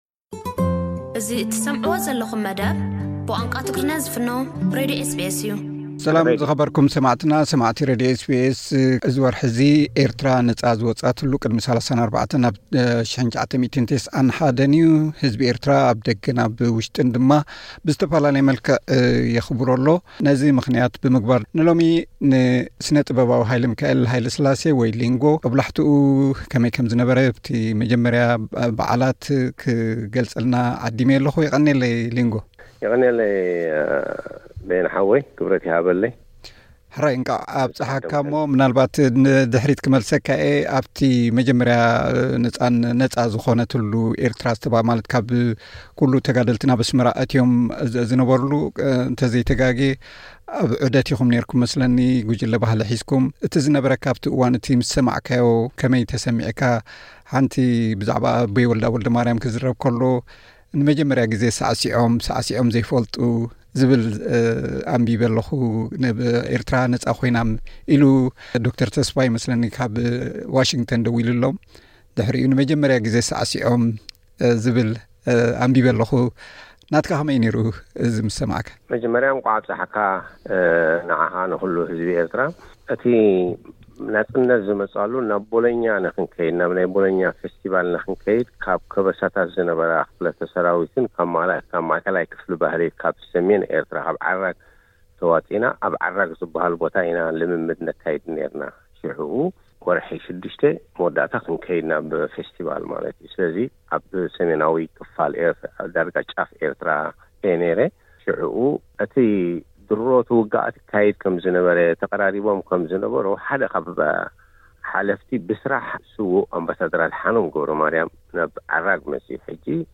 ሓጺር ዕላል።